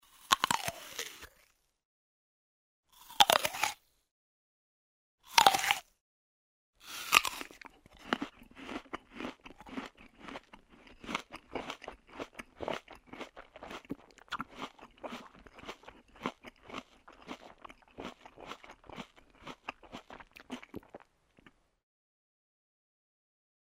Кусание сочного яблока, тщательное жевание, плавное проглатывание